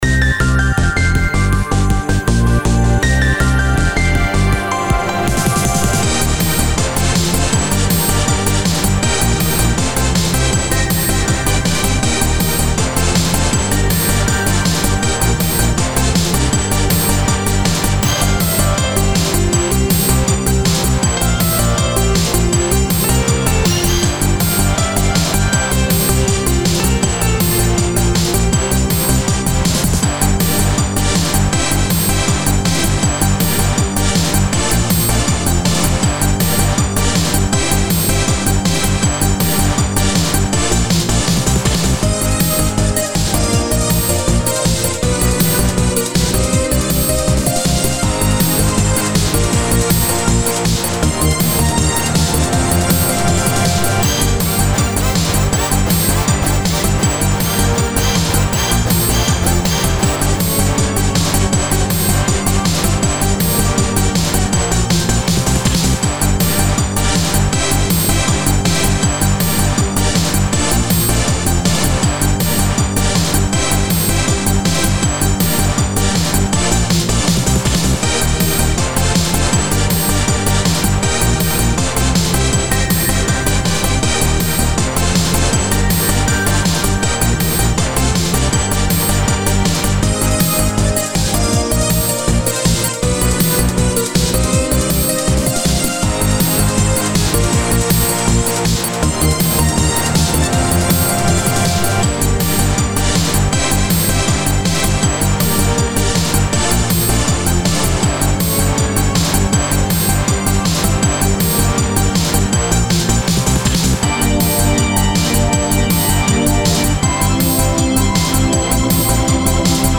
Some unused eurodance-style project music.